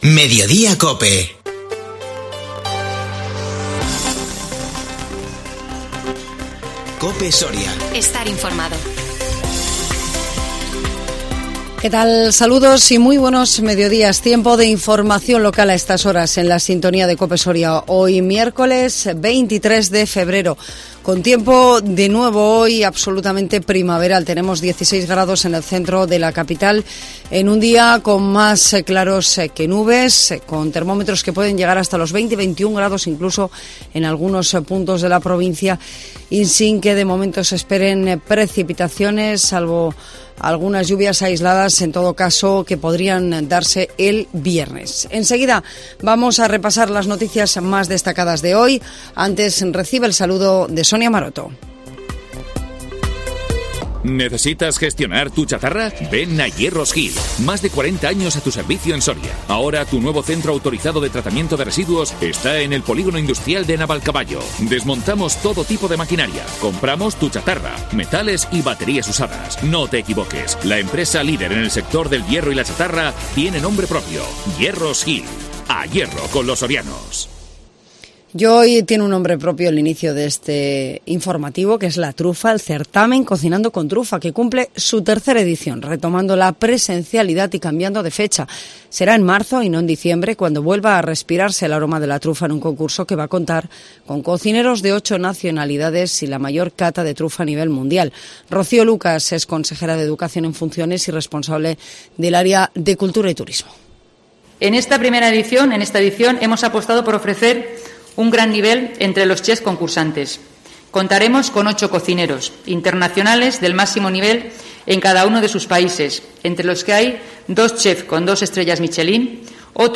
INFORMATIVO MEDIODÍA COPE SORIA 23 FEBRERO 2022